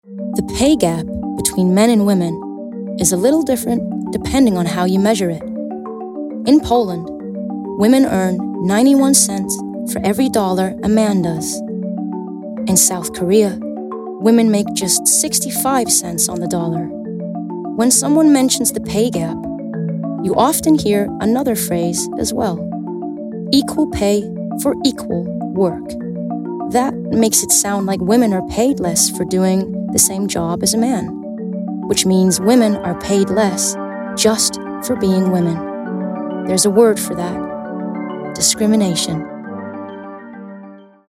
Female
Warm